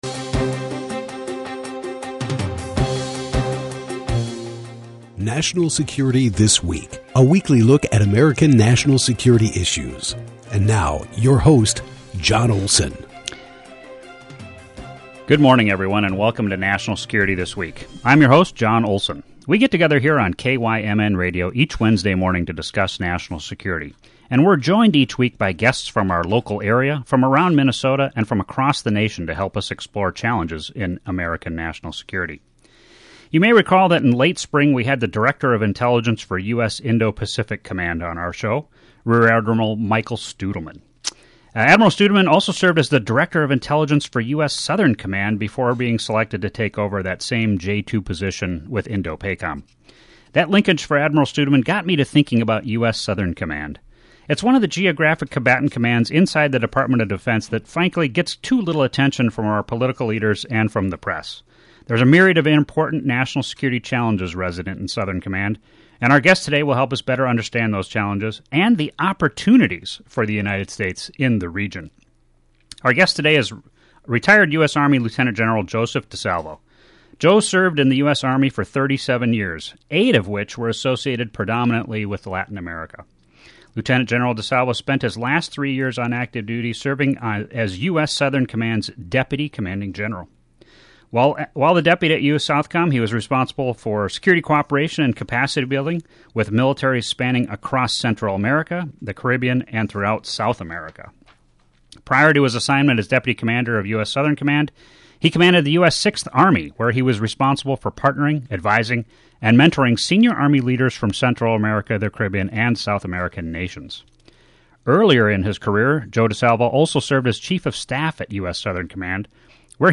talks with retired US Army Lieutenant General Joseph DiSalvo, the former Deputy Commander of U.S. Southern Command. They discuss the current state of affairs for SOUTHCOM, regional challenges, and opportunities in the region in support of American national security interests.